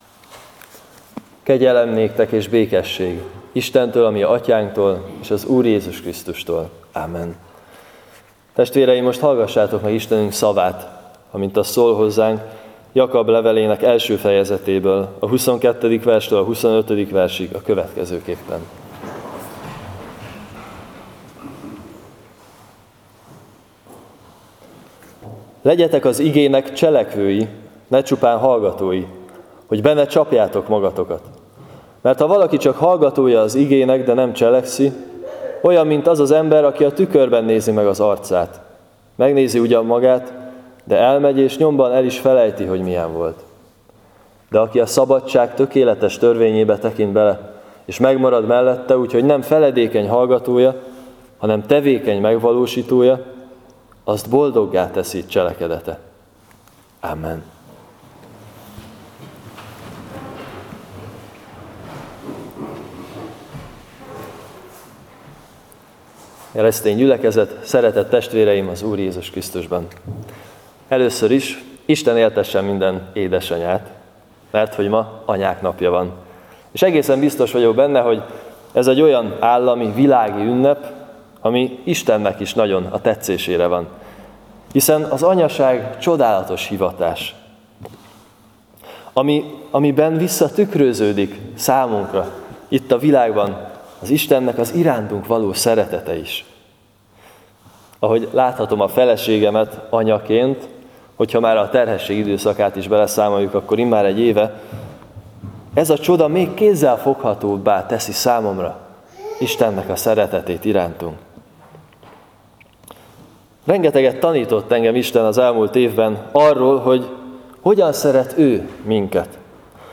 05.01. Jak 1-22-25 igehirdetes.mp3 — Nagycserkeszi Evangélikus Egyházközség